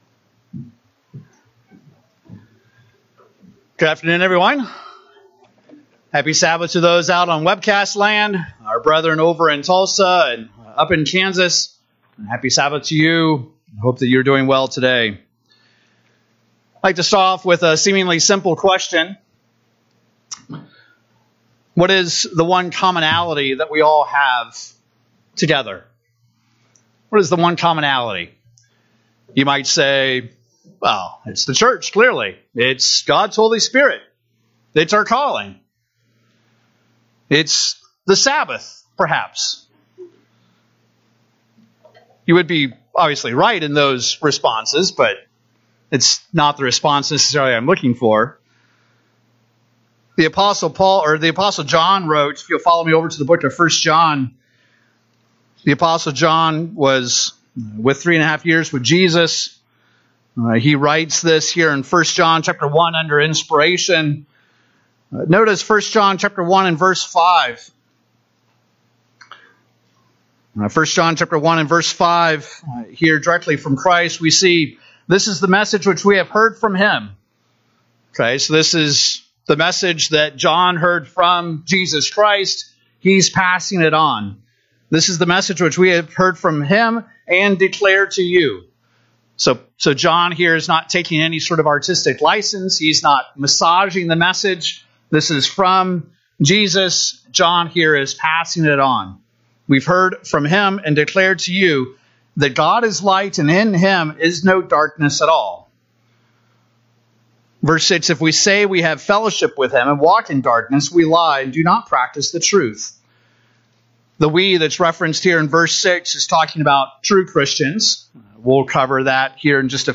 In this sermon we will explore what sin actually is and what God expects from us.